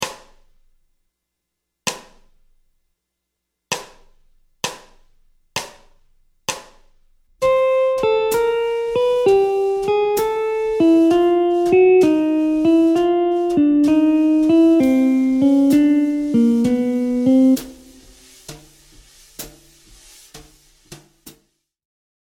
Gamme Majeure ( IV – mode Lydien)
Descente de gamme
Gamme-bop-desc-Pos-31-C-Lydien-1.mp3